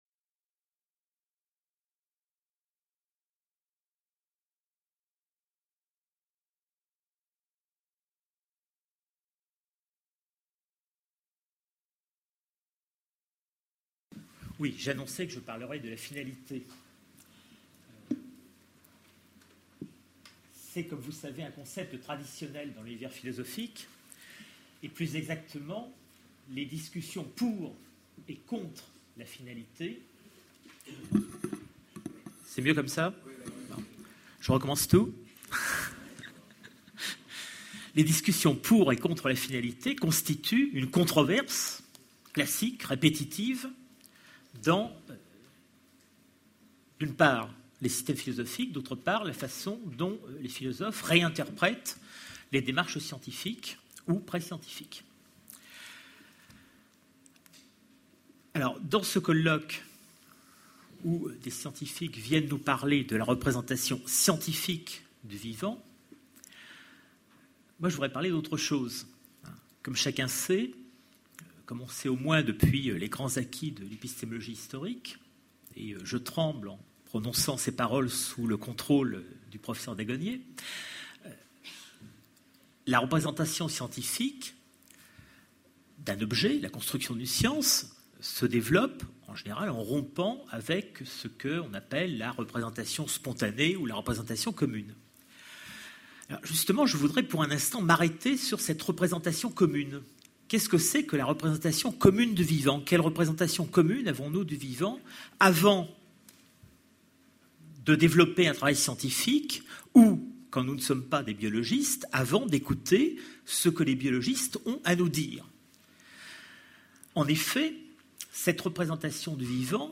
Colloque La représentation du vivant : du cerveau au comportement